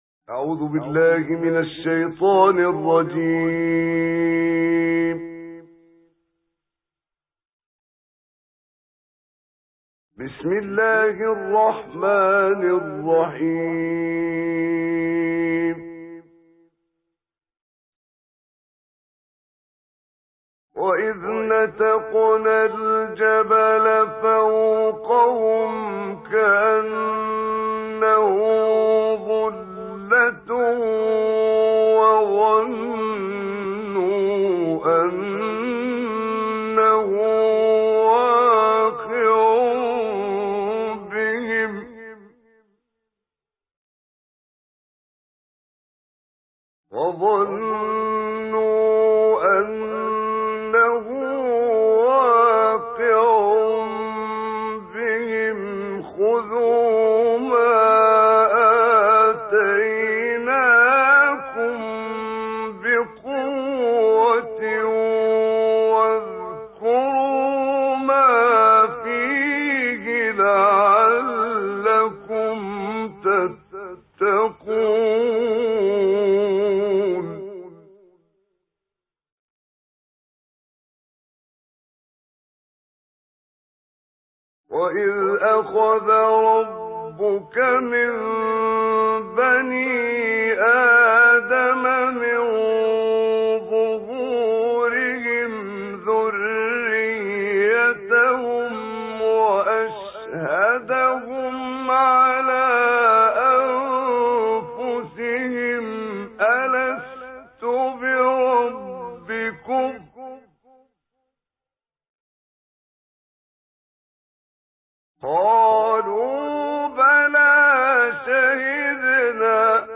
أجمل تلاوة للشيخ محمد عمران